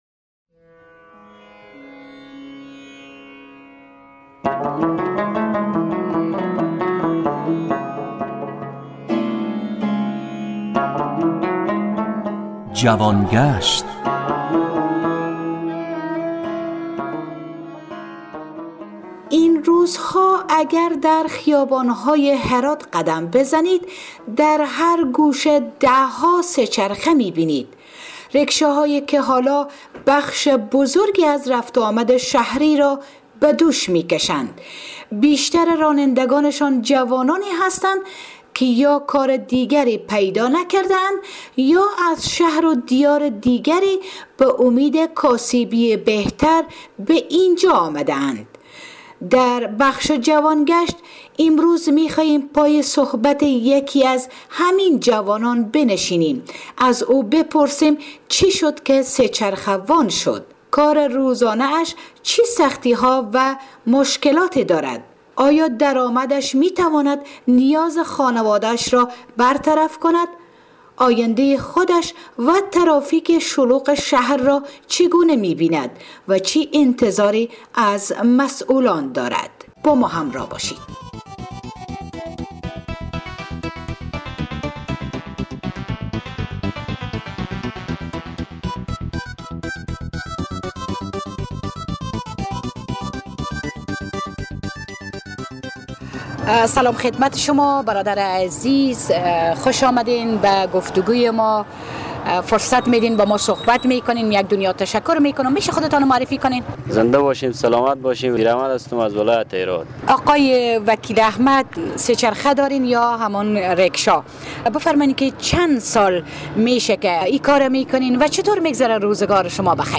با یک جوان هراتی که به ریکشا وانی مشغول است